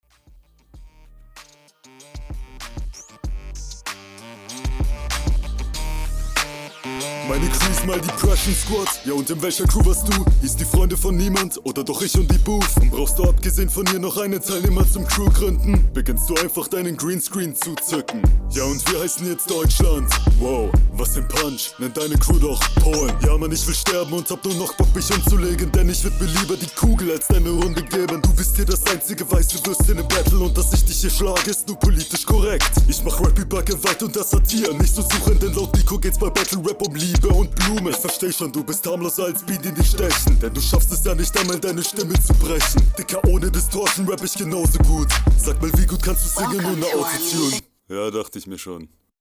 Battle Runden
Audio absolut stabil, ein ganz kleines Bisschen weniger abwechslungsreich als die …